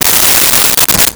Silverware Movement 01
Silverware Movement 01.wav